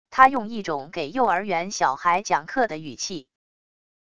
他用一种给幼儿园小孩讲课的语气wav音频生成系统WAV Audio Player